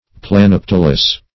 Search Result for " planipetalous" : The Collaborative International Dictionary of English v.0.48: Planipetalous \Plan`i*pet"al*ous\, a. [Plani- + petal.]